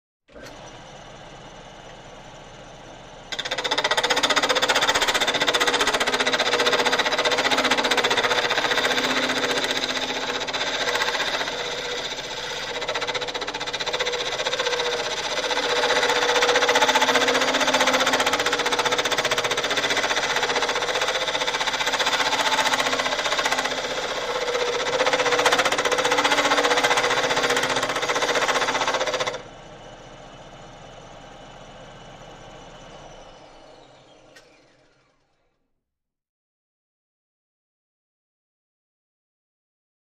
Lathe, Motorized, Turn On, Wind Up, Steady with Metallic Rattling, Off with Short Wind Down.